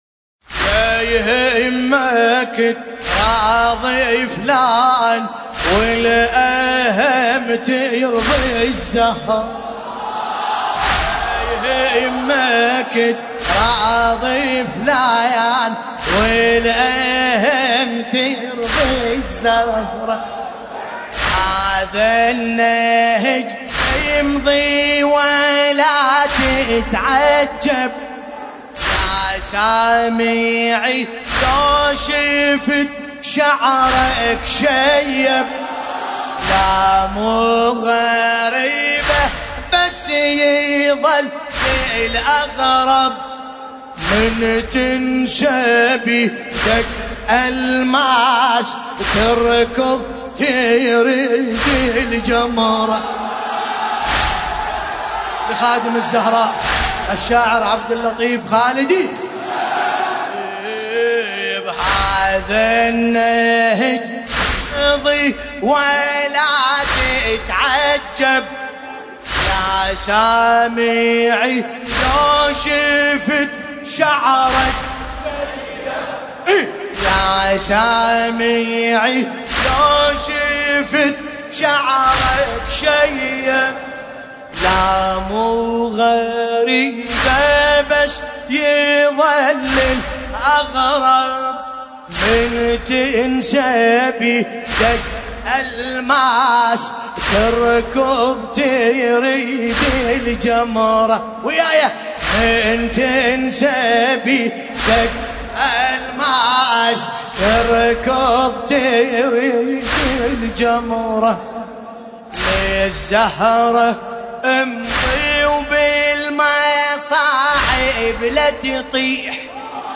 المناسبة : الليالي الفاطمية 1440
طور : ميمر _كربلائي حسينية قصر الزهراء - الكاظمية المقدسة